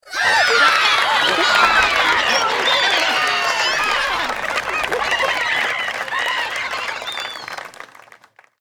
Golf_Crowd_Applause.ogg